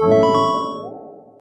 Techmino/media/effect/chiptune/win.ogg at bb89665fff3cd2ea1111bcd48090fcb039178c01
win.ogg